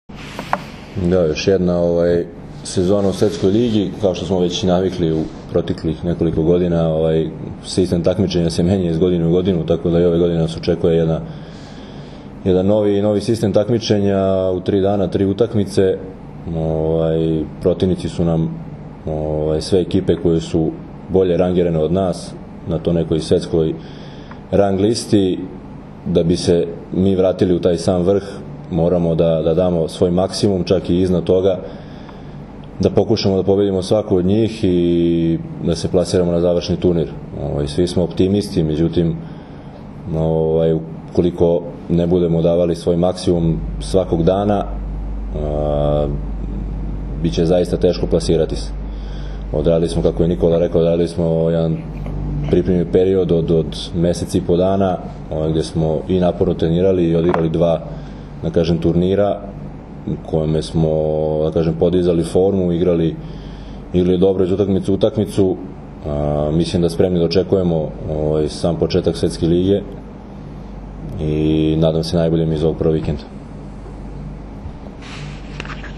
Tim povodom, danas je u beogradskom hotelu “M” održana konferencija za novinare, na kojoj su se predstavnicima medija obratili Nikola Grbić, Dragan Stanković, Aleksandar Atanasijević i Miloš Nikić.
IZJAVA DRAGANA STANKOVIĆA